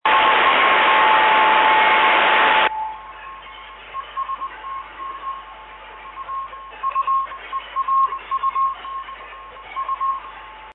la riduzione del rumore è drastica ed il segnale diventa ben comprensibile.
Tali files sono stati registrati in situazioni reali, sulle bande radioamatoriali.